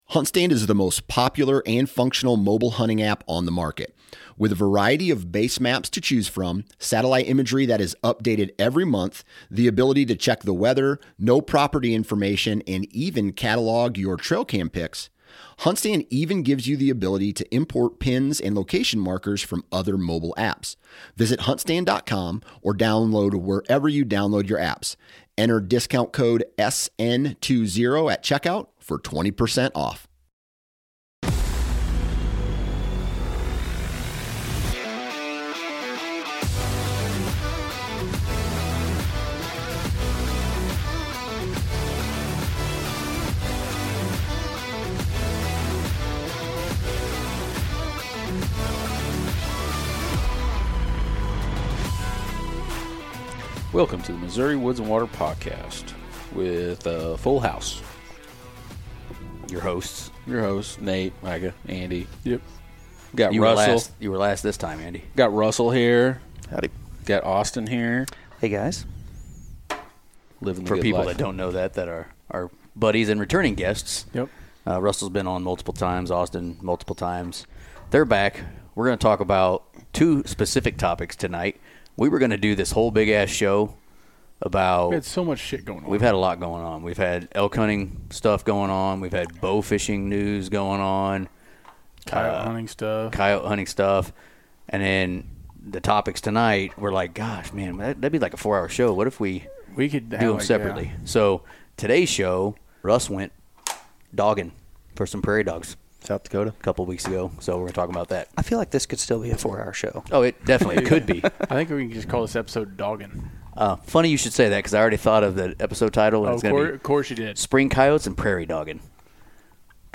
We get into the different ways we go about deploying trail cams in the woods when it isn't hunting season. Today's show is a good discussion with two guys who don't do things the same way with cameras this time of year so you can get several points of view here.